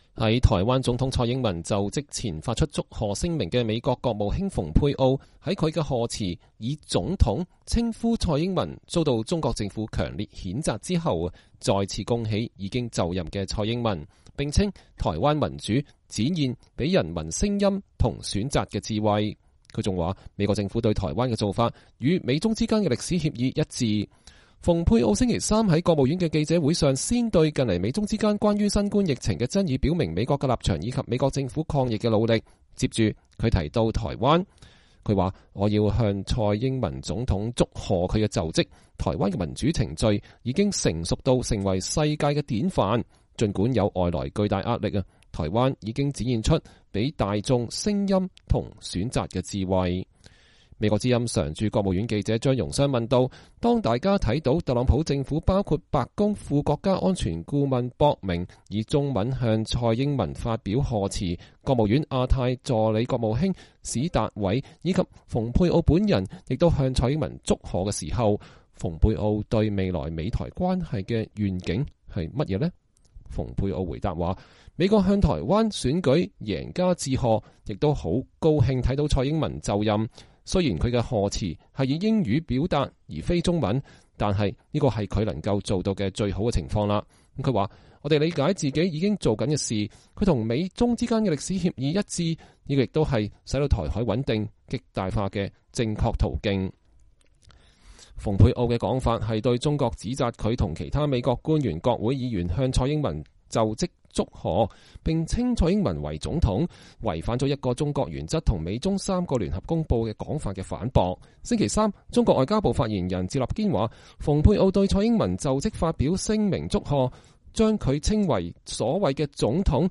美國國務卿蓬佩奧在國務院舉行記者會 (2020年5月20日)